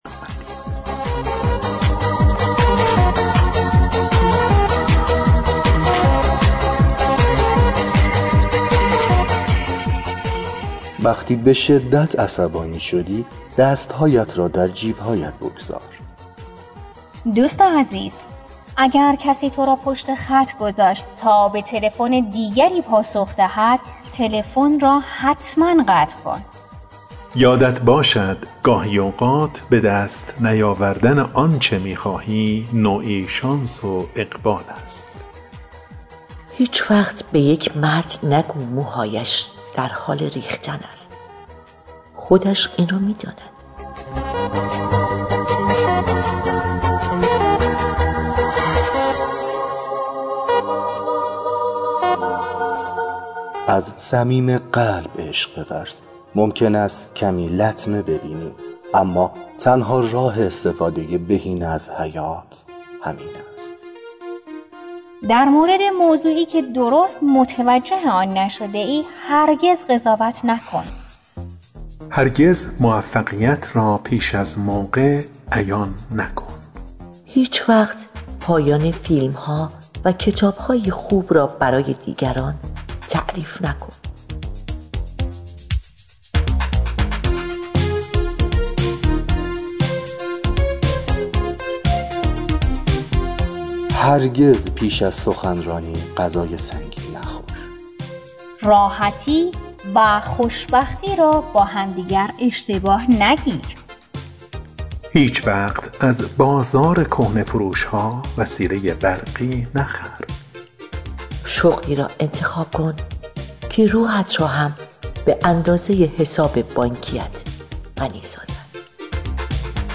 اجرای گروهی